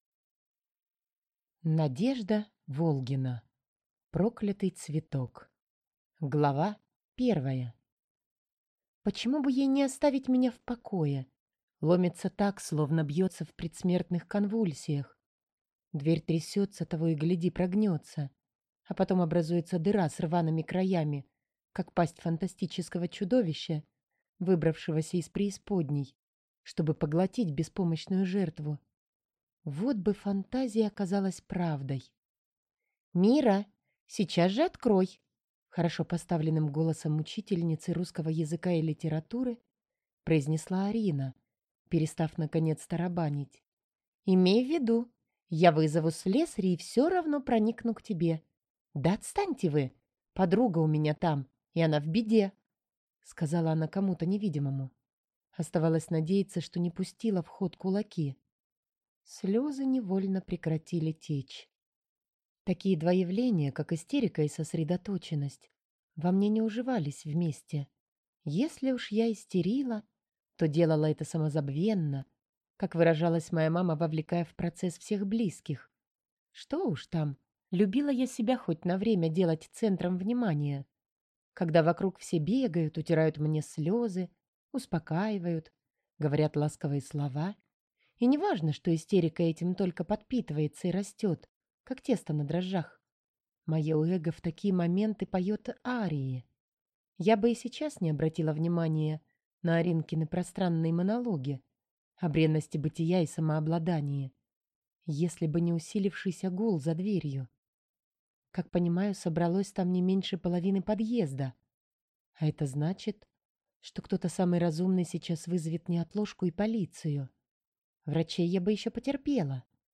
Аудиокнига Проклятый цветок | Библиотека аудиокниг